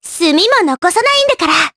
Cleo-Vox_Skill4_jp.wav